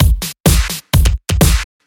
Electrohouse Loop 128 BPM (38).wav